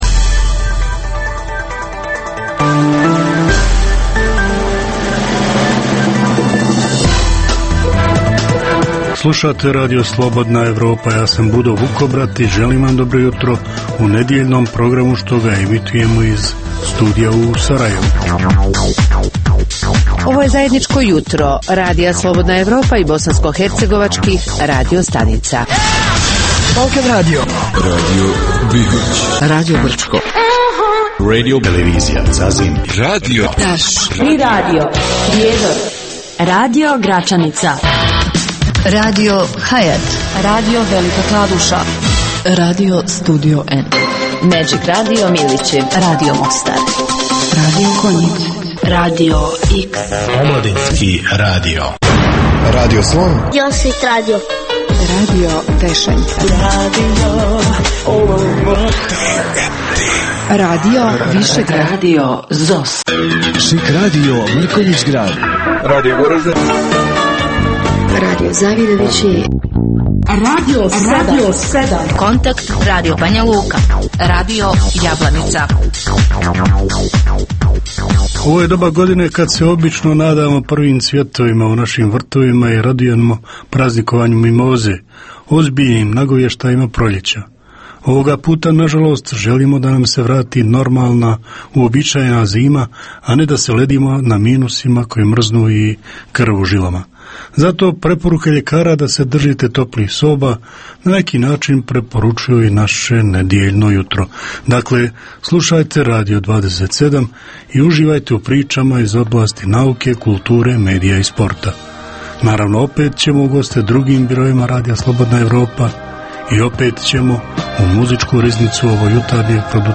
Jutarnji program namijenjen slušaocima u Bosni i Hercegovini. Uz vijesti i muziku, poslušajte pregled novosti iz nauke i tehnike, te čujte šta su nam pripremili novinari RSE iz regiona.